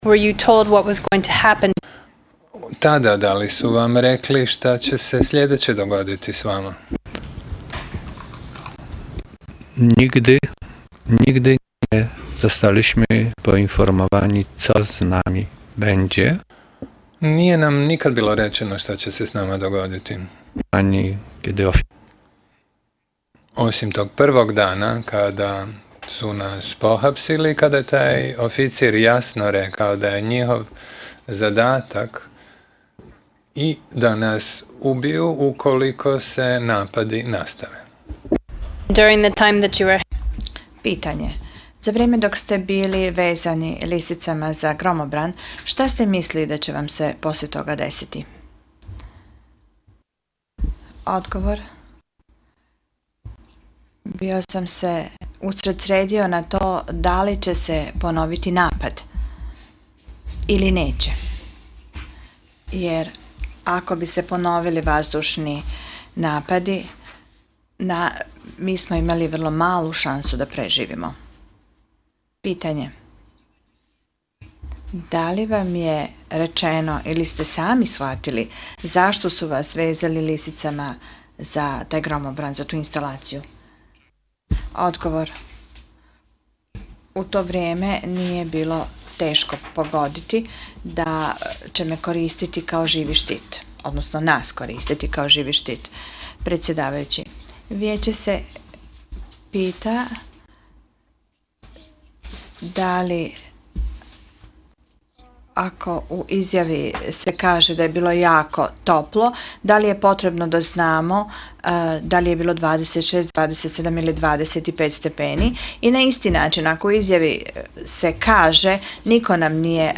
Dio iskaza o korištenju pripadnika UN kao živi štit